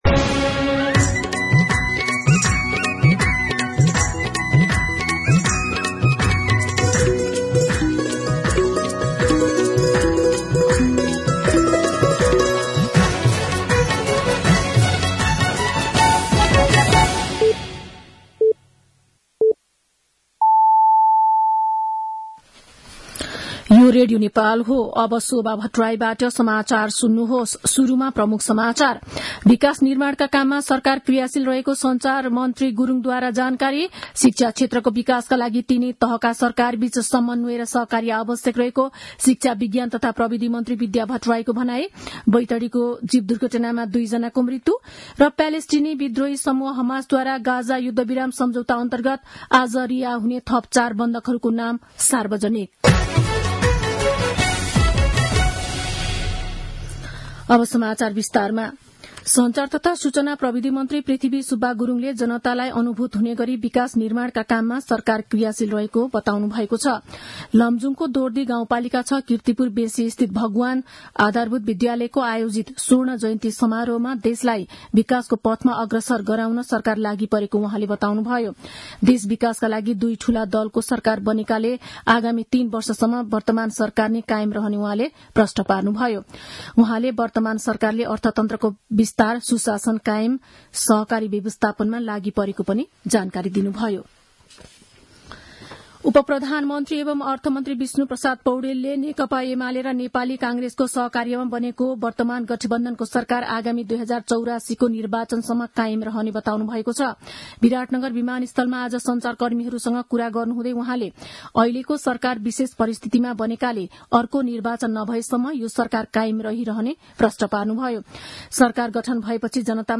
दिउँसो ३ बजेको नेपाली समाचार : १३ माघ , २०८१